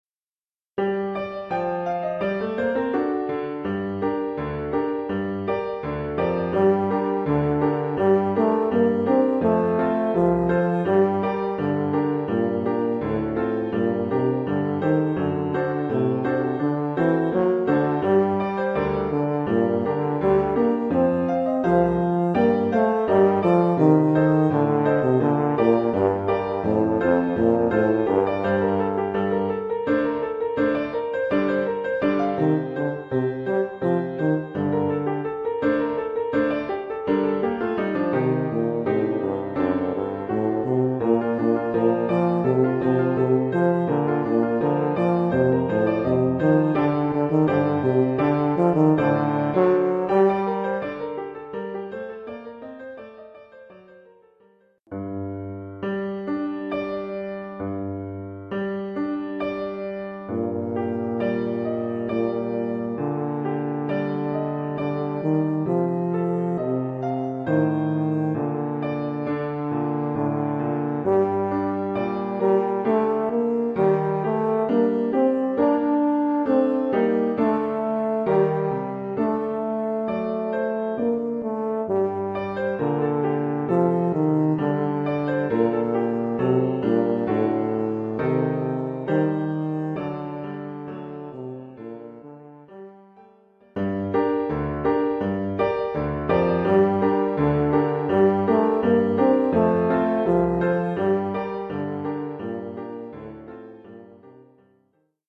Oeuvre pour saxhorn basse / euphonium /
tuba et piano.
(instrument avec accompagnement de piano et piano seul).